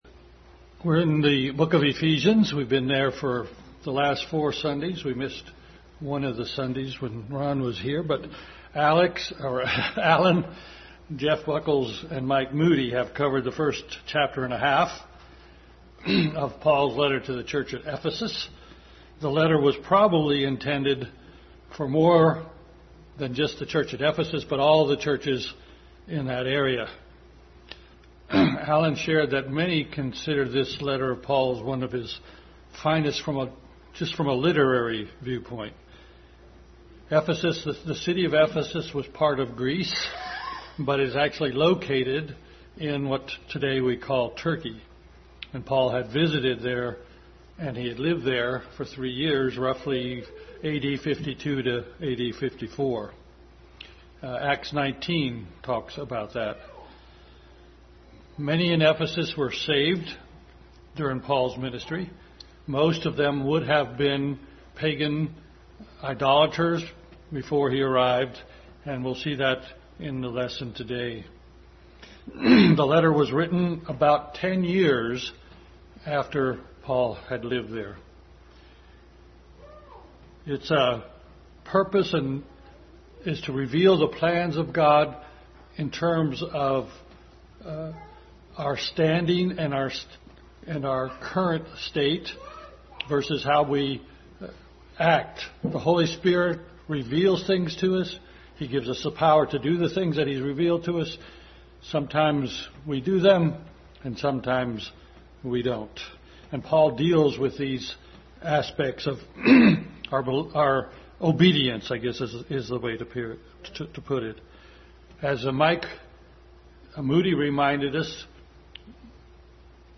Adult Sunday School continued study in Ephesians.